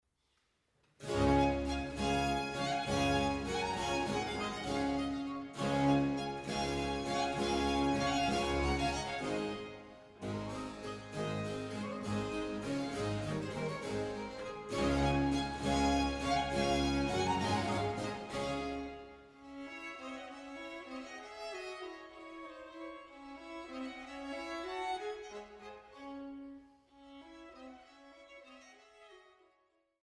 Cembalo und musikalische Leitung
Ensemble für Alte Musik